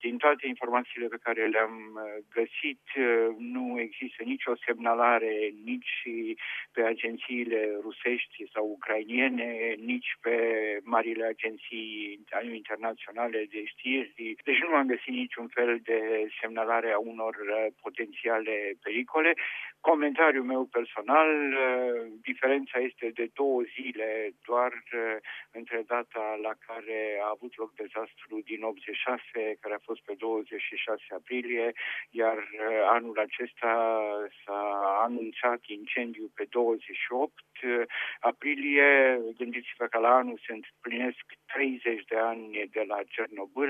Fizicianul